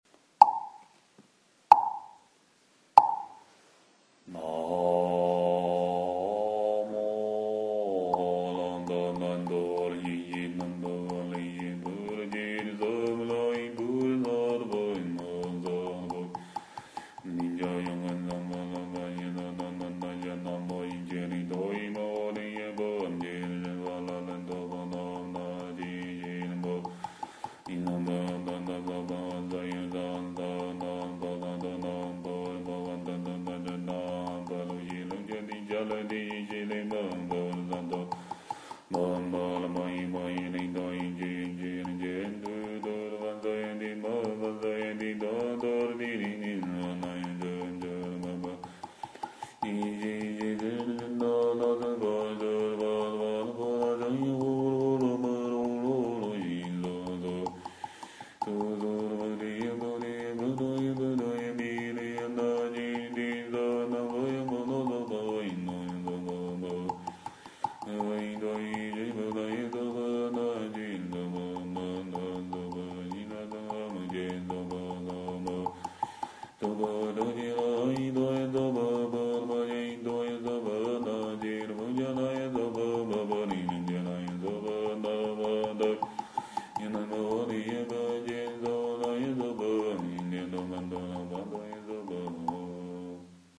大悲咒-念诵
诵经
佛音 诵经 佛教音乐 返回列表 上一篇： 大悲咒 下一篇： 心经 相关文章 六字大明咒 六字大明咒--齐豫...